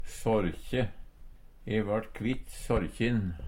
sårkje - Numedalsmål (en-US)